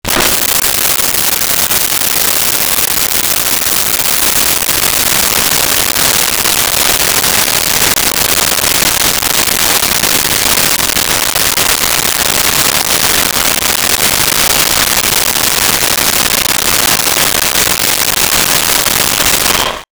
Toilet Flush 3
toilet-flush-3.wav